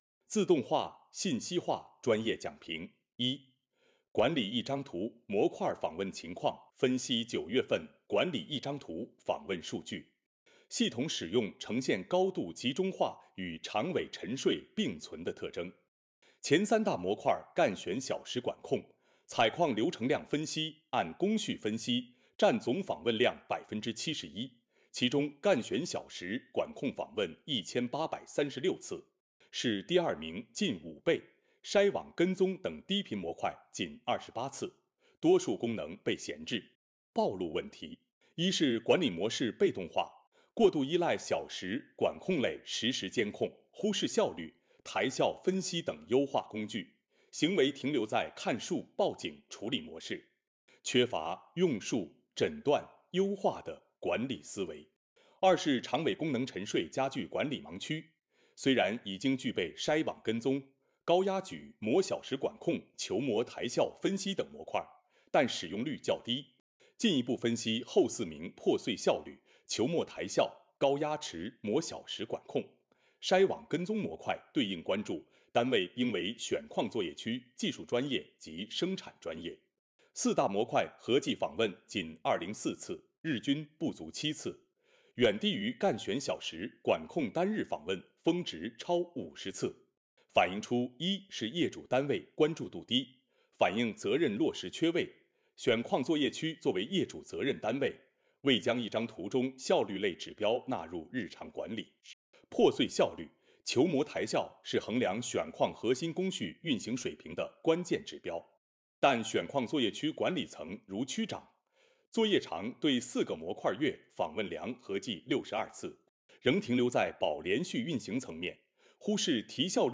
B站开发的tts工具 IndexTTS2 – 伴塘